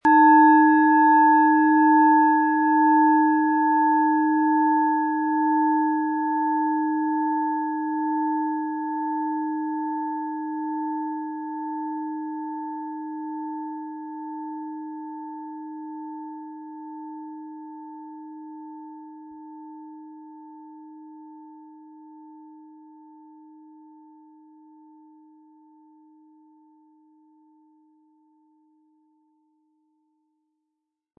Planetenschale® Venus, Ø 14,6 cm, inkl. Klöppel im Sound-Spirit Shop | Seit 1993
Diese tibetische Planetenschale Venus ist von Hand gearbeitet.
Wie klingt diese tibetische Klangschale mit dem Planetenton Venus?
SchalenformBihar
MaterialBronze